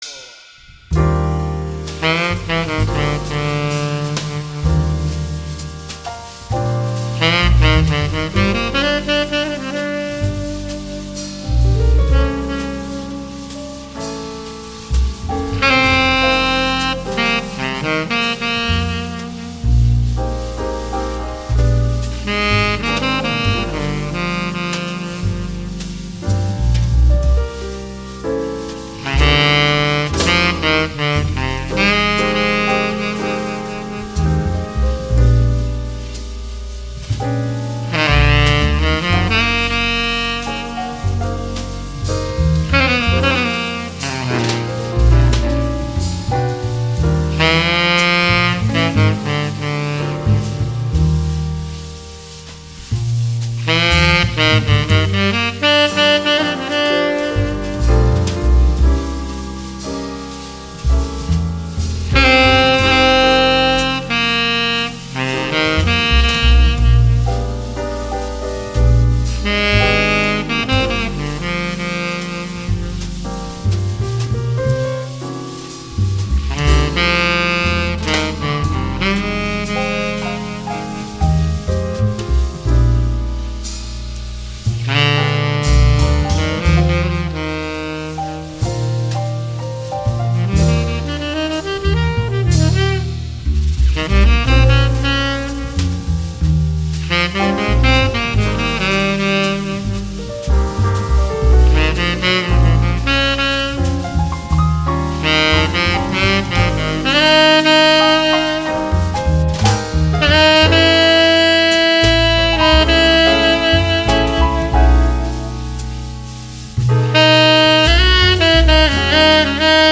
Jazz, Latin Jazz and Latin Music